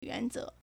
原则 (原則) yuánzé
yuan2ze2.mp3